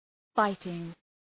Shkrimi fonetik {‘faıtıŋ}